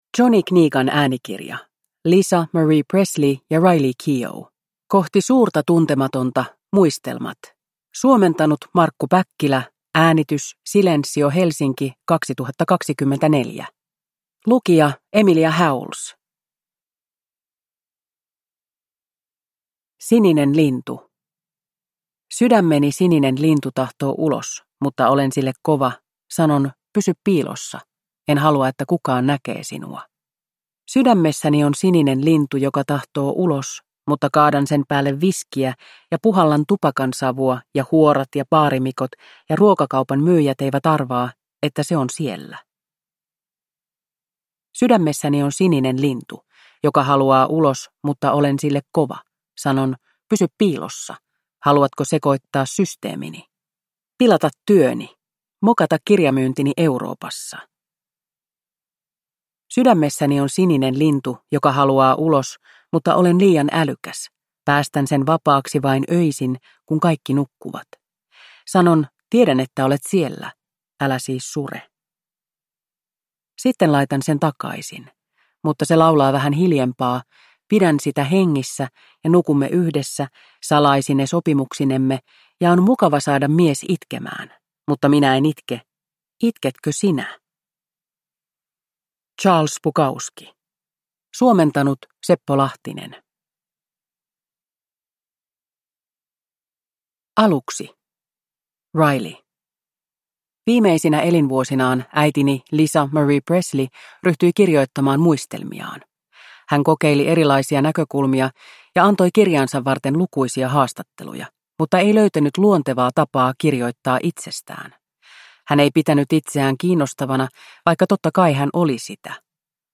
Kohti suurta tuntematonta – Muistelmat – Ljudbok